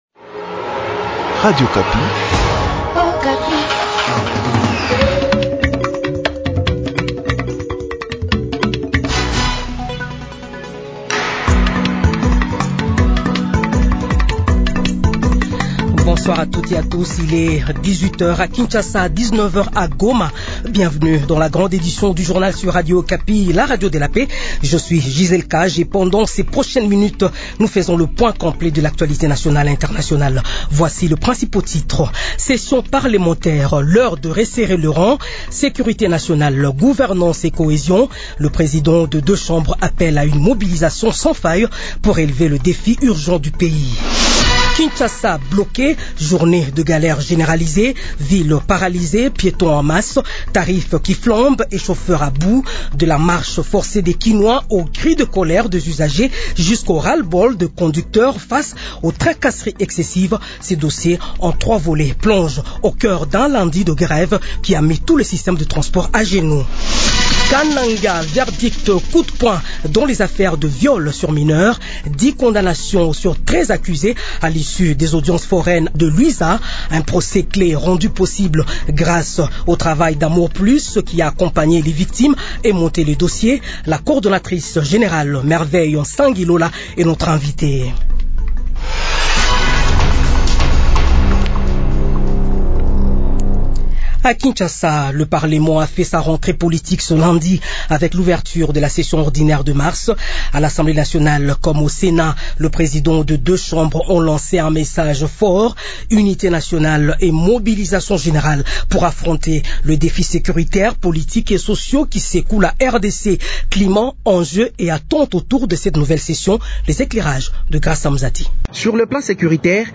Journal Soir
Edition de 18 heures du lundi 16 mars 2026